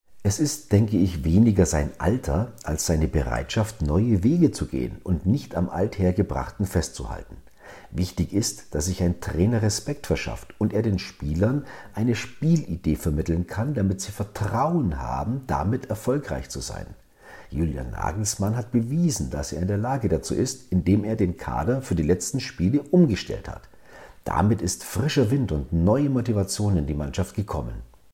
ehemaligem Leistungssportler, Mental- und Kommunikationscoach.